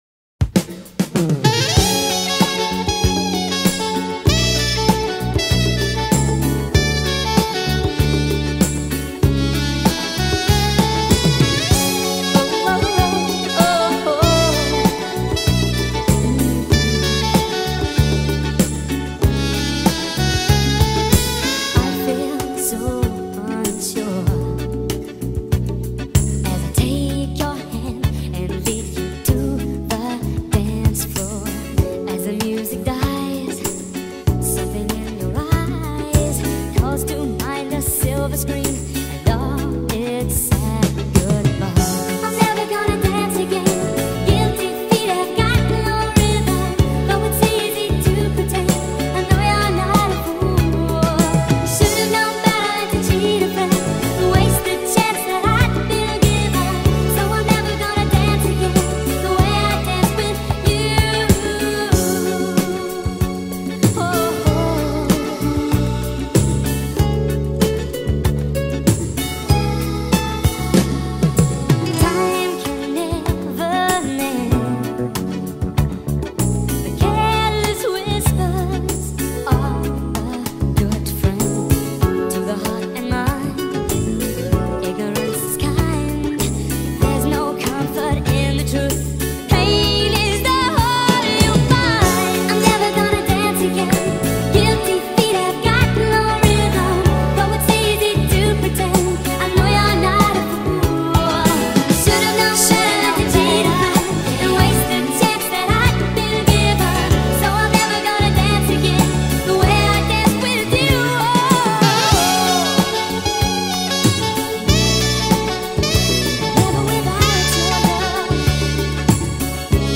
با ریتمی تند شده
عاشقانه